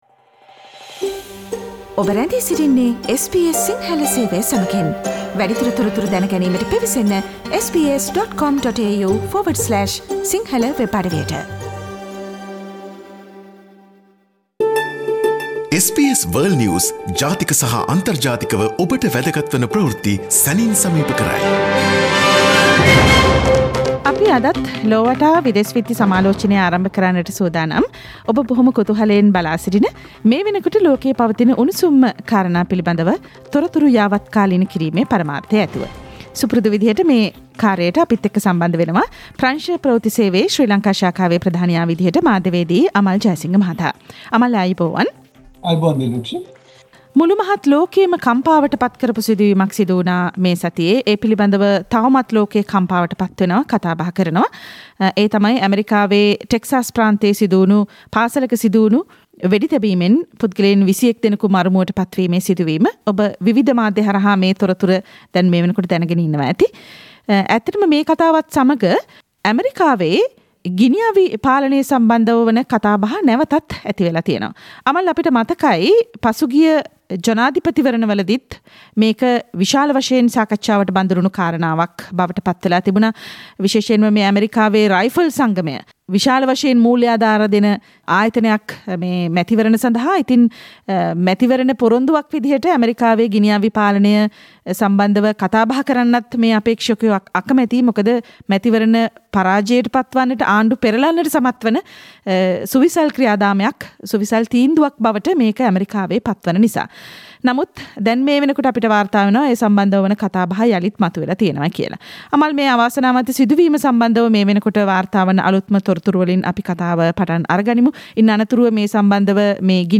and the world news critic World's prominent news highlights in a few minutes - listen to SBS Si Sinhala Radio's weekly world News wrap on Friday Share